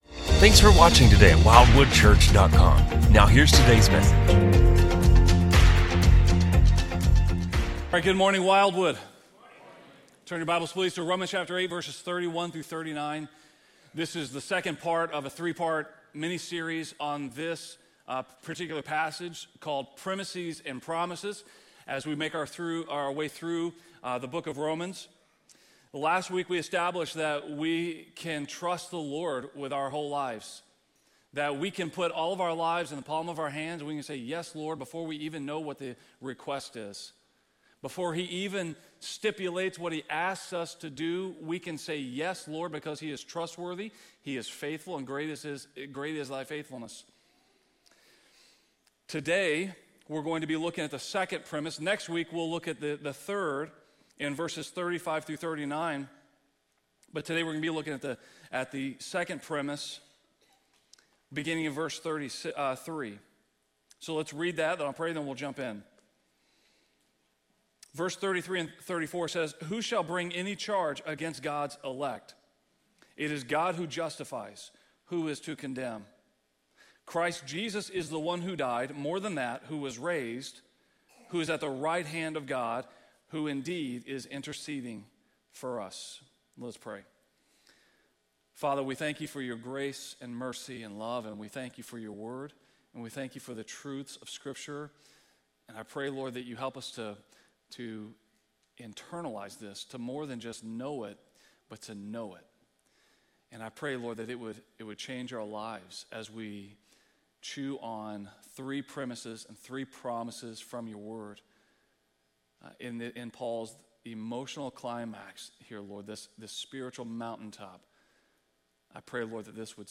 We invite you to listen to this sermon and find freedom in Christ! In this sermon we’ll see that because God does not condemn those who are in Christ, no one can condemn us.